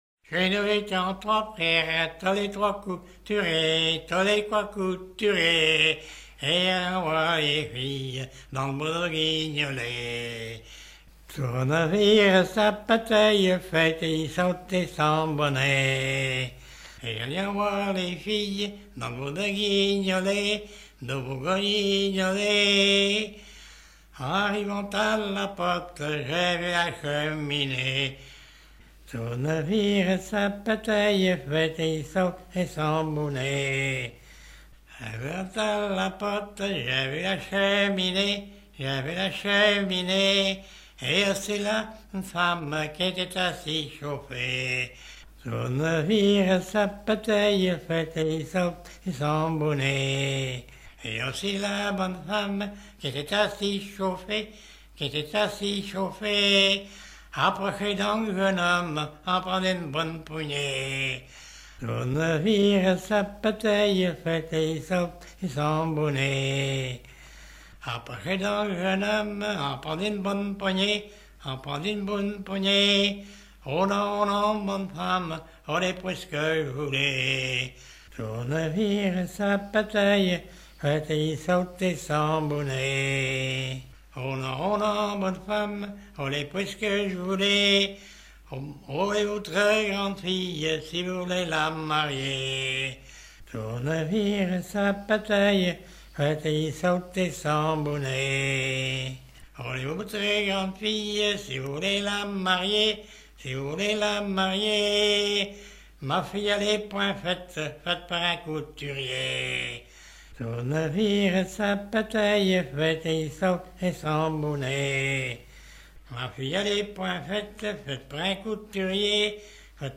Genre laisse
Pièce musicale éditée